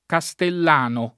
kaStell#no] s. m. e agg.